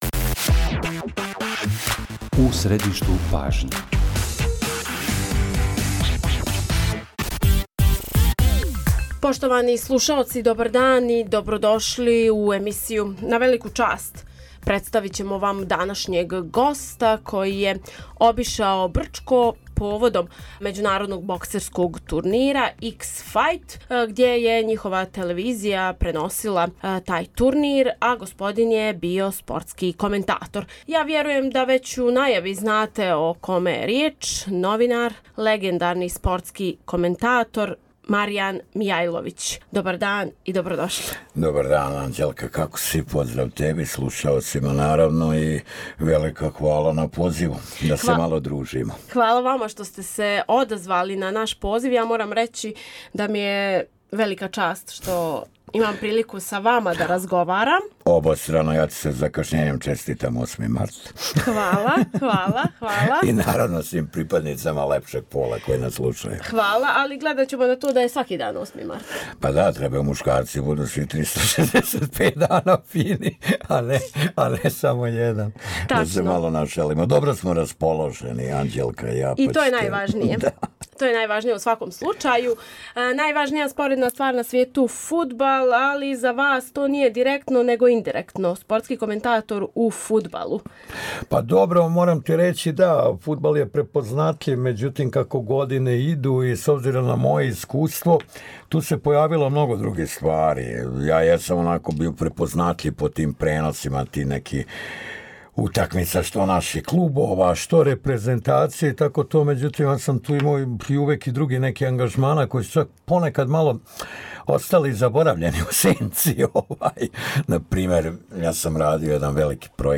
У искреном разговору открива детаље из каријере и поглед на савремено спортско новинарство.